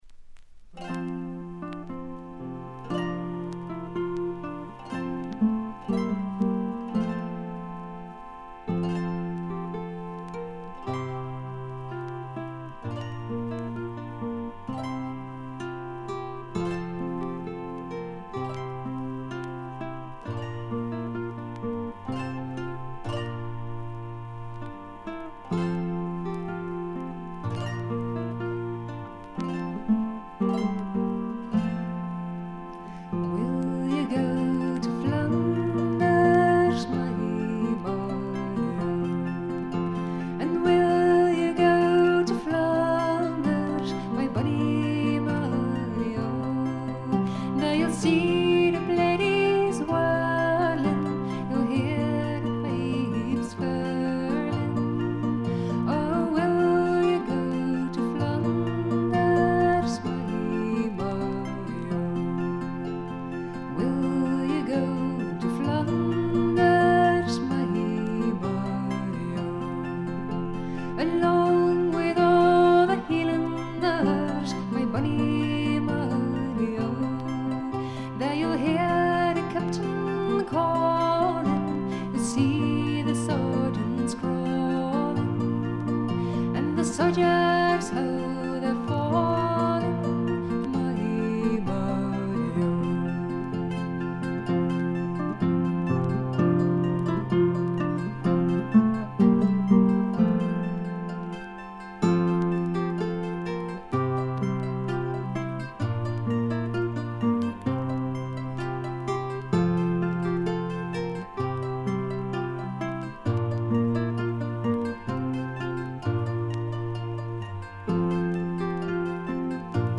バックグラウンドノイズ、チリプチ多め大きめ。
オランダのトラッド・フォーク・グループ
試聴曲は現品からの取り込み音源です。